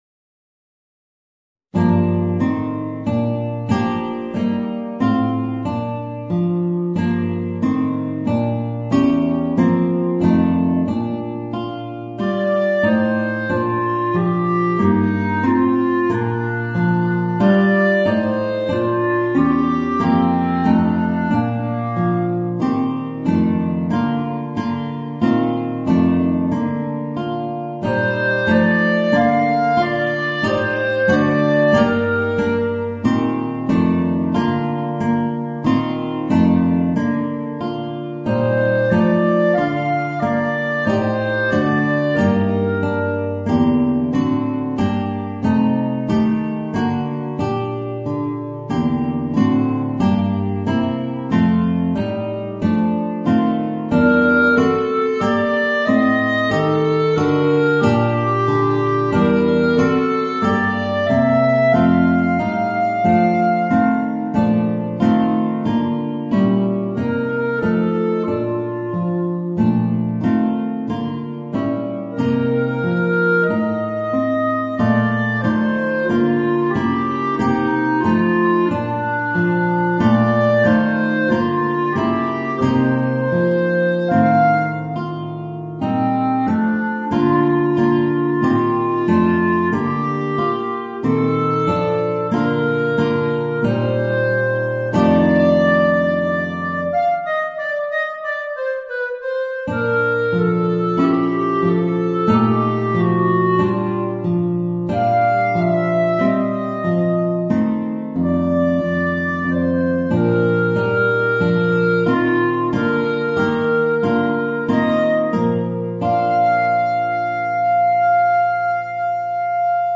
Voicing: Guitar and Clarinet